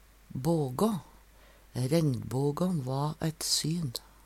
bågå - Numedalsmål (en-US)